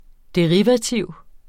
Udtale [ deˈʁivaˌtiwˀ ]